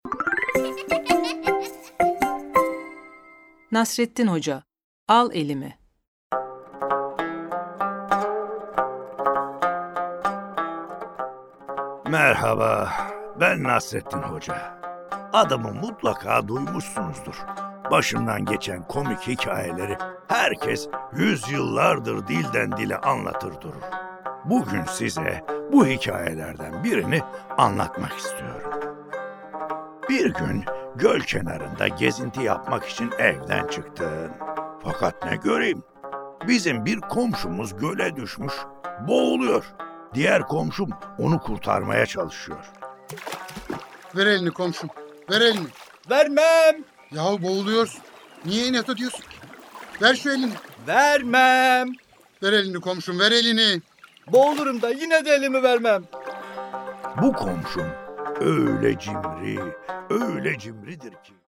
Nasreddin Hoca: Al Elimi Tiyatrosu